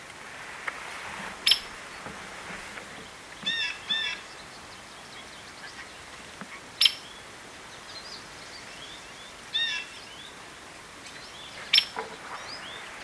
3. Hairy Woodpecker (Dryobates villosus)
Sharp “peek!” and a fast, rattling drum.